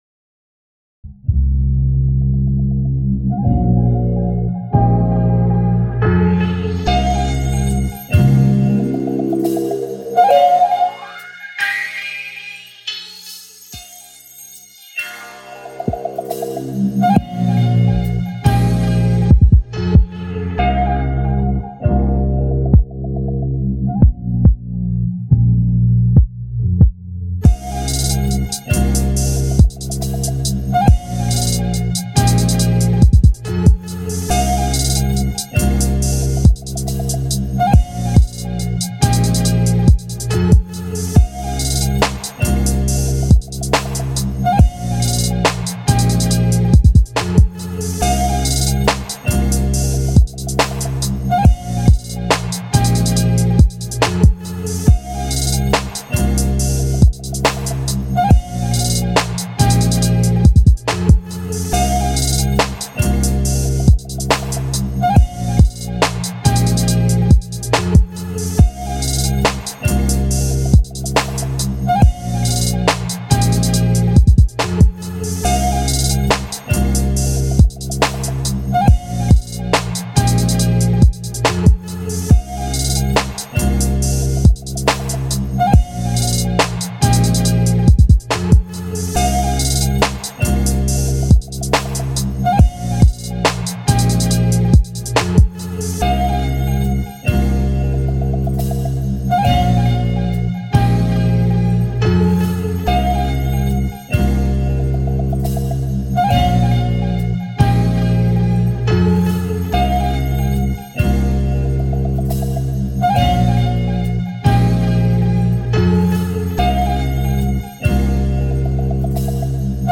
Remove the rather excessive hi-hats (translation: make it more old school)
140 BPM
Music / 90s
lofi jazzy soul sample beat chill hip hop rap remix beats trap garageband piano bass
soulful house dance echo rhythm and blues